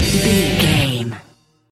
Aeolian/Minor
drums
electric guitar
bass guitar
hard rock
metal
lead guitar
aggressive
energetic
intense
powerful
nu metal
alternative metal